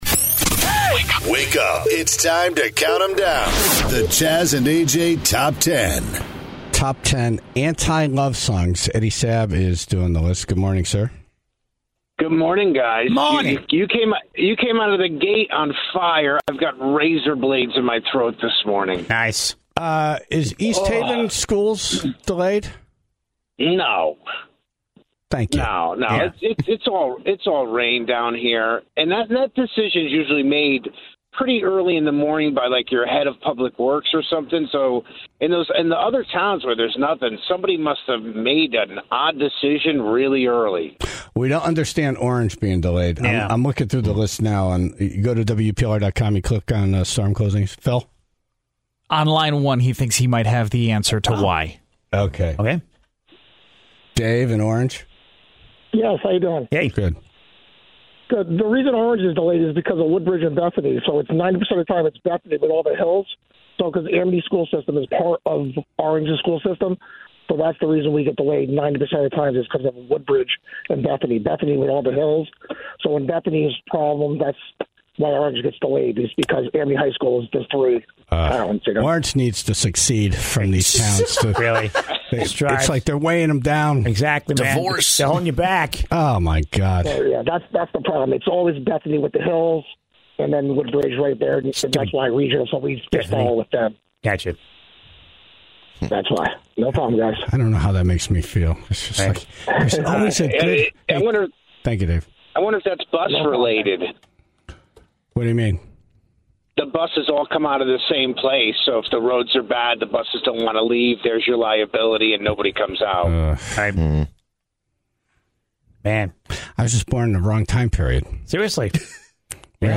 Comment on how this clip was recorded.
was on the phone